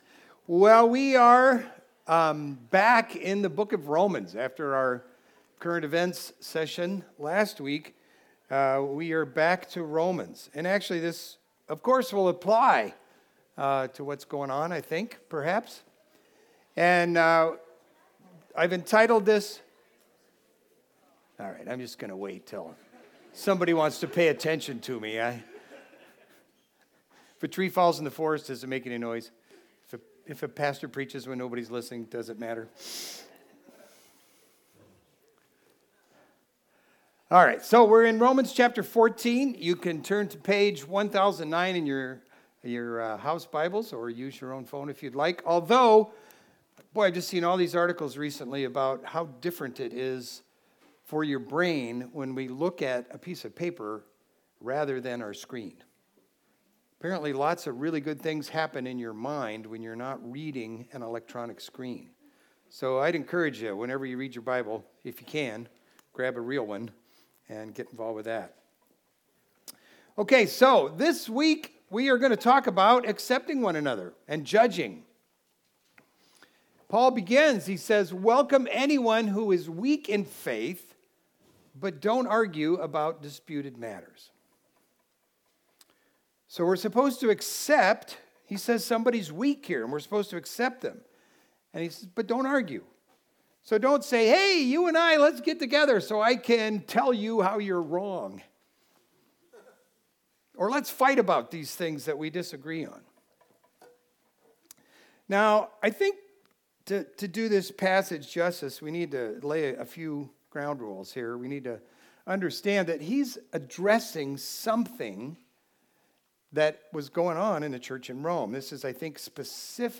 Video Audio Download Audio Home Resources Sermons God Will Be the Judge of That Feb 01 God Will Be the Judge of That Paul urges the Christians in Rome to accept one another and leave the judgment to God.